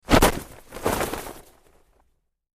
Cloth; Parachute Opening, Various Cloth Flap And Movement.